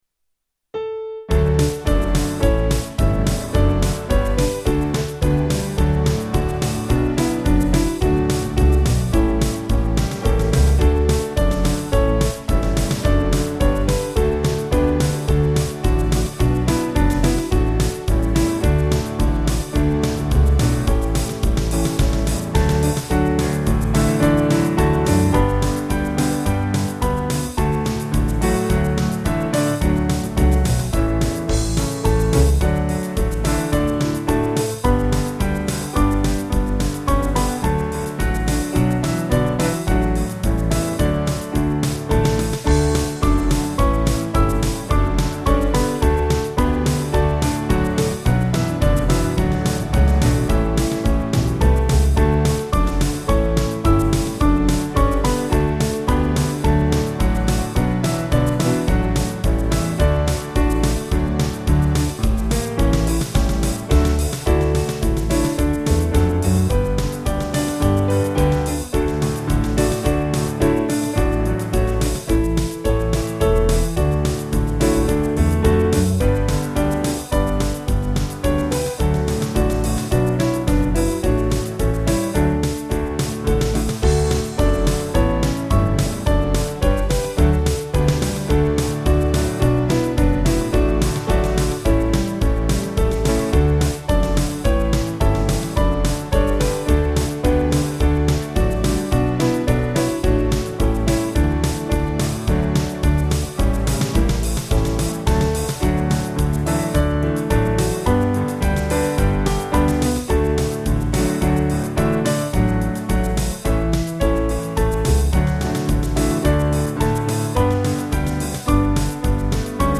8.8.8.8 with Refrain
Small Band